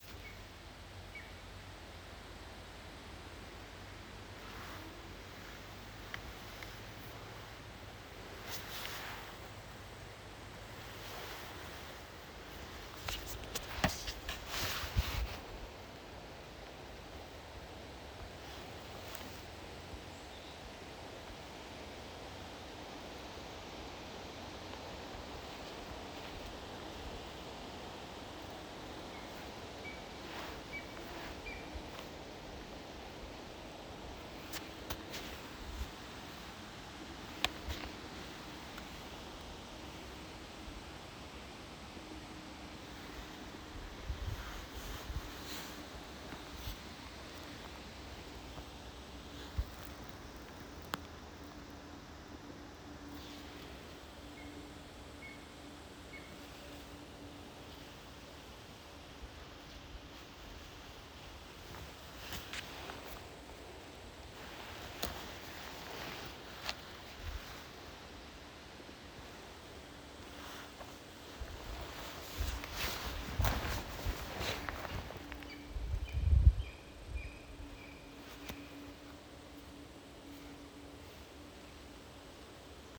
Птицы -> Хищные птицы ->
малый подорлик, Clanga pomarina
Blakus bija dzirdami saucieni.